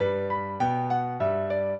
piano
minuet2-7.wav